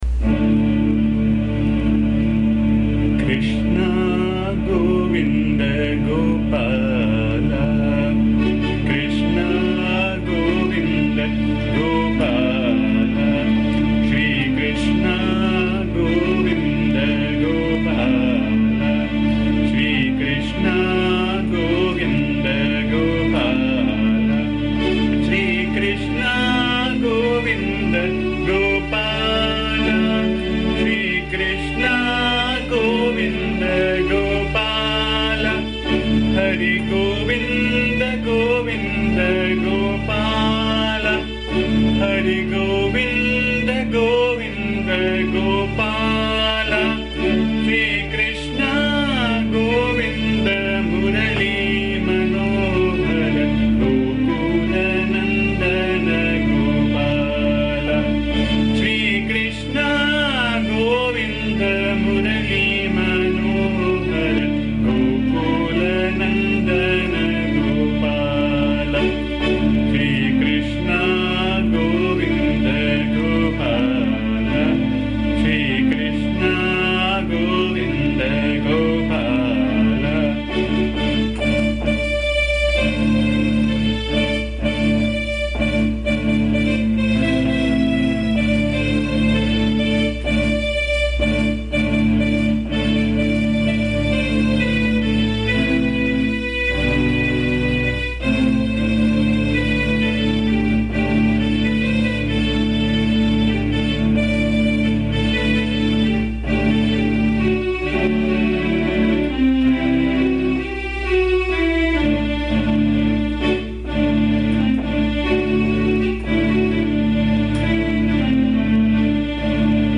This is a song with simple lyrics praising Krishna as the gopaala of Gokula. The song has been recorded in my voice which can be found here. Please bear the noise, disturbance and awful chanting as am not a singer.
AMMA's bhajan song